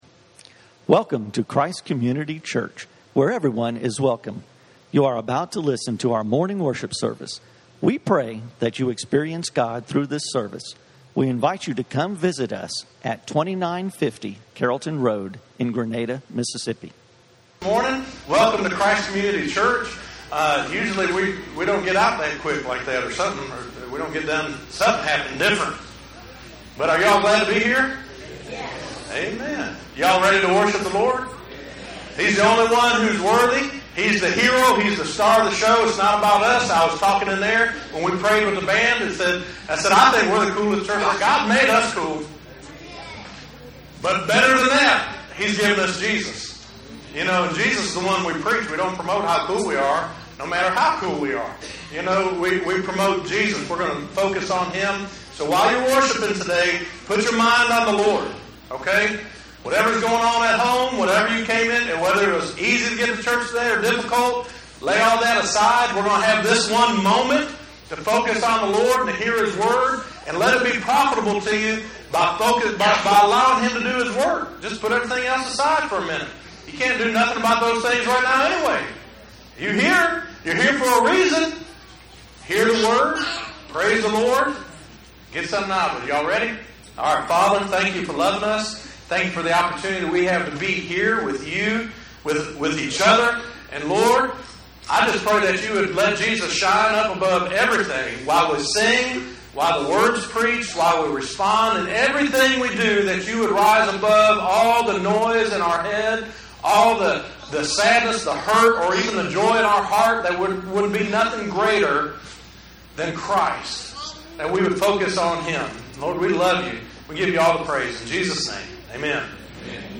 JESUS Is The Answer - Messages from Christ Community Church.